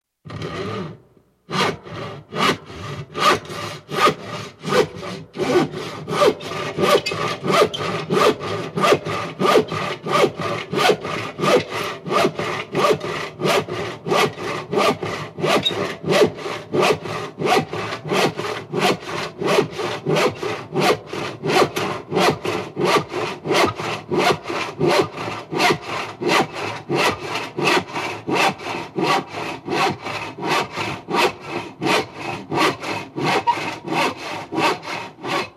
Hand sawing - Ручная пила (ножовка)
Отличного качества, без посторонних шумов.
176_hand-sawing.mp3